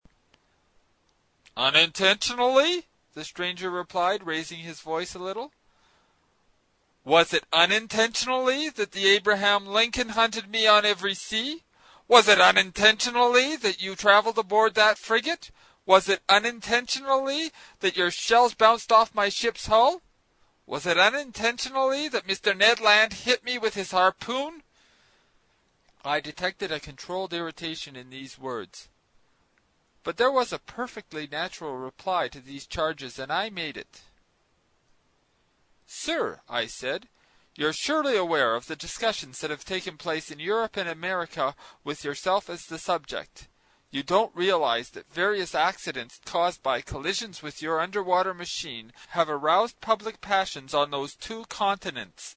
英语听书《海底两万里》第133期 第10章 水中人(4) 听力文件下载—在线英语听力室
在线英语听力室英语听书《海底两万里》第133期 第10章 水中人(4)的听力文件下载,《海底两万里》中英双语有声读物附MP3下载